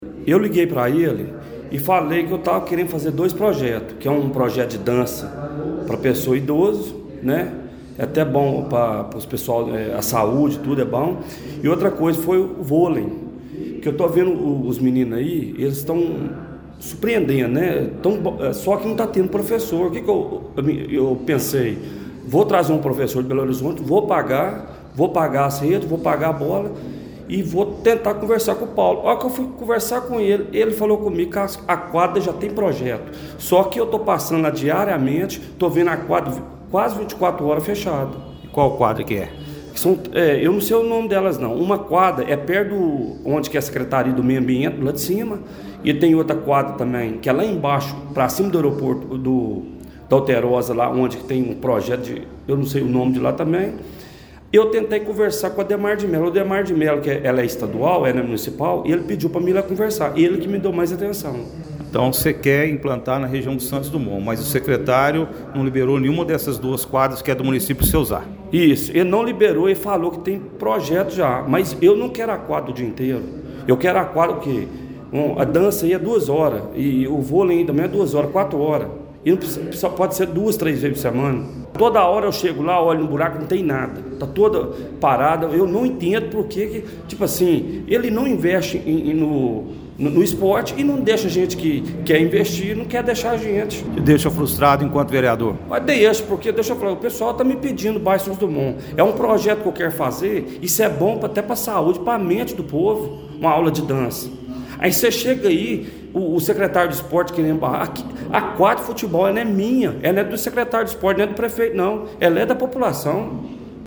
Durante a reunião da Câmara Municipal realizada, ontem 2 de dezembro, o vereador Claudinei Secundino do Nascimento, o Dinei Calha, fez um desabafo público após ter seu pedido negado pelo secretário municipal de Esporte, Lazer e Turismo, Paulo Francisdale.